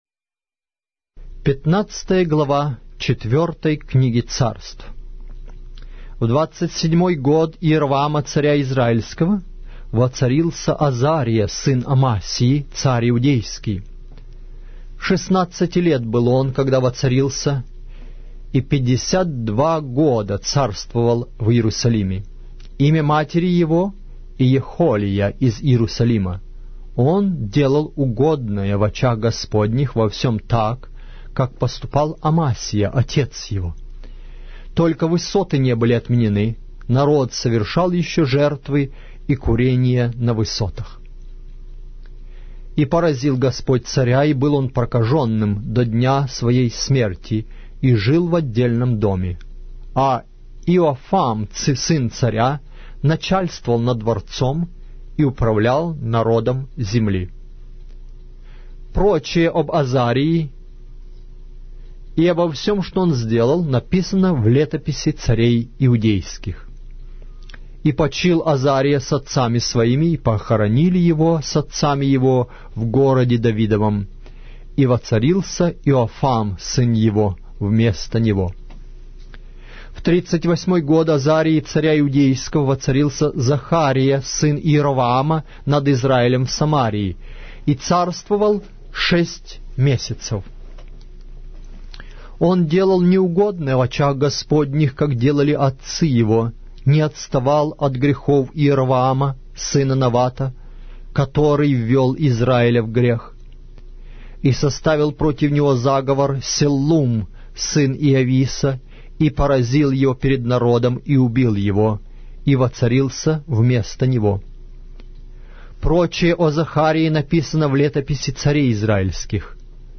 Аудиокнига: 4-я Книга Царств